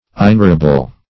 Inerrable \In*er"ra*ble\, a. [L. inerrabilis.